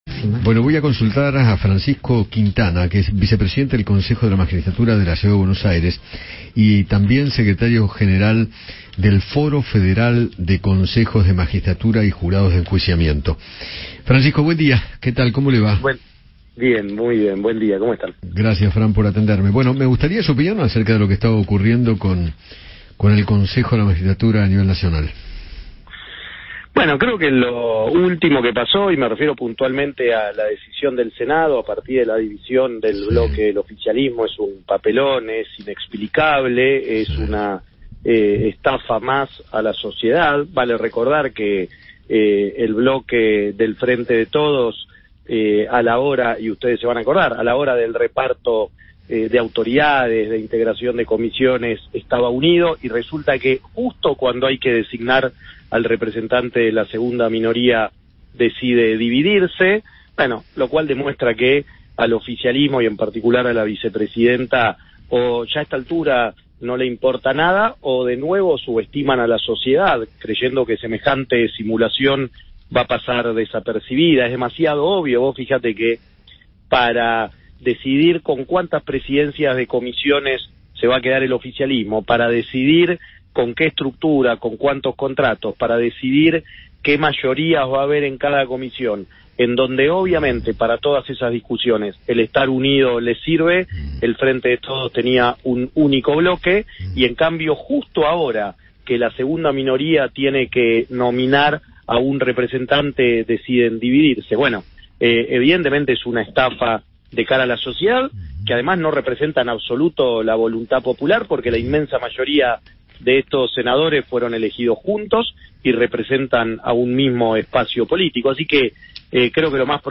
Francisco Quintana, vicepresidente del Consejo de la Magistratura de la Ciudad de Buenos Aires, habló con Eduardo Feinmann sobre la maniobra del oficialismo en el Senado.